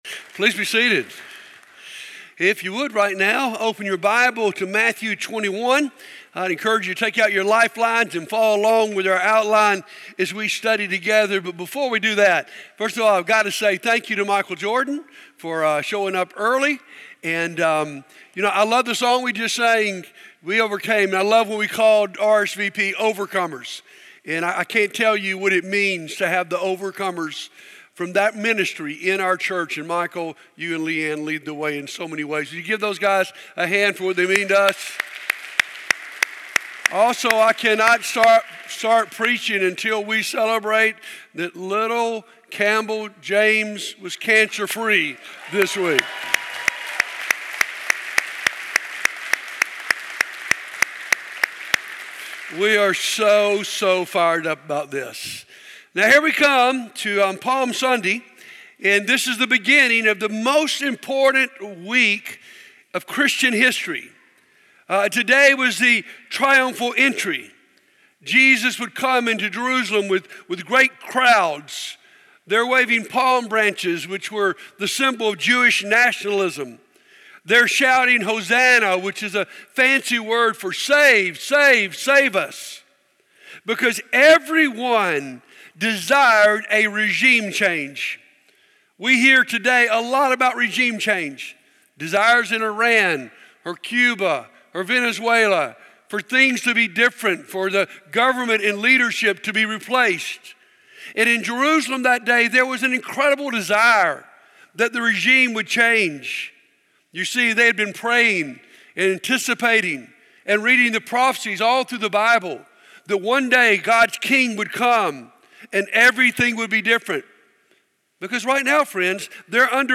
A message from the series "Standalone Messages."